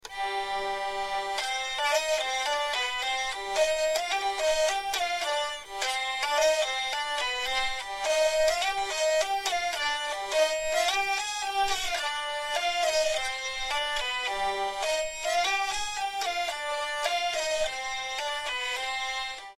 Au mieux, on trouvera ici des morceaux enregistrés avec des moyens modestes par un exécutant modeste.
L'enregistrement a été effectué en utilisant un micro branché directement sur l'ordinateur (voir fiche sur le paramétrage du micro).
Bourrée à deux temps traditionnelle. Partition pour vielle en sol-do.
Sur une seule chanterelle.